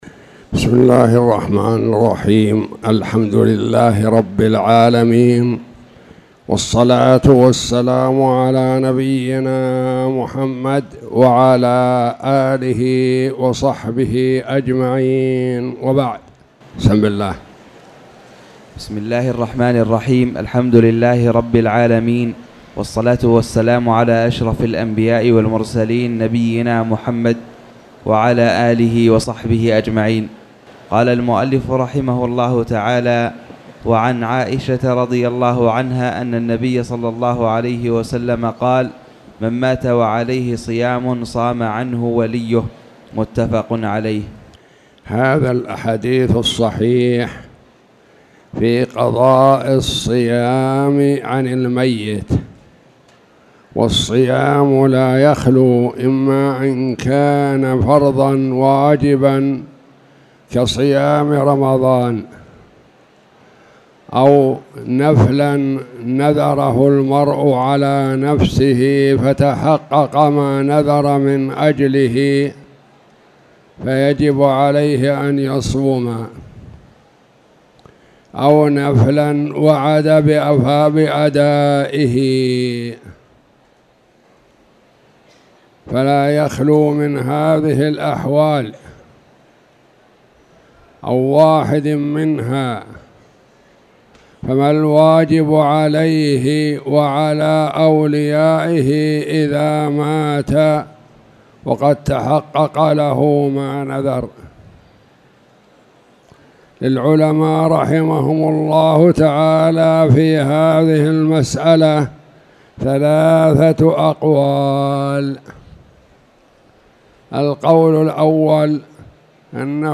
تاريخ النشر ٥ شوال ١٤٣٧ هـ المكان: المسجد الحرام الشيخ